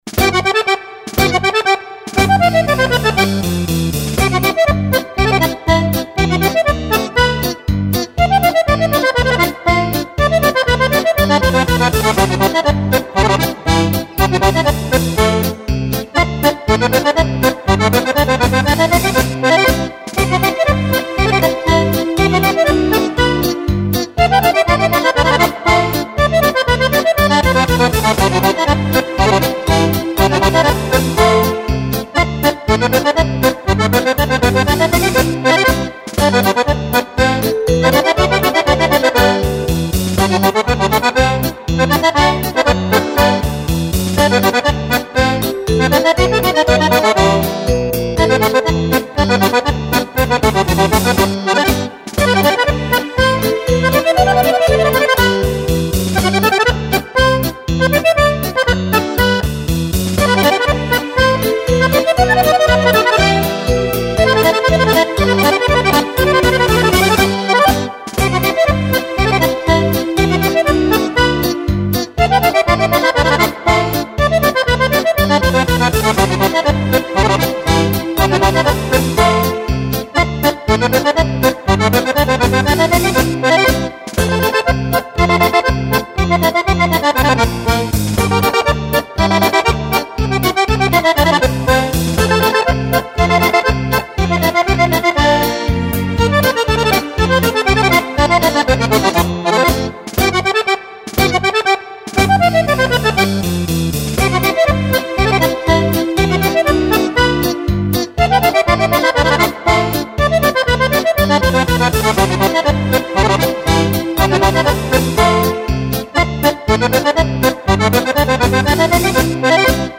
polca
Polca per Fisarmonica